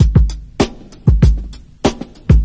• 131 Bpm Breakbeat A Key.wav
Free drum loop - kick tuned to the A note. Loudest frequency: 1316Hz
131-bpm-breakbeat-a-key-7zi.wav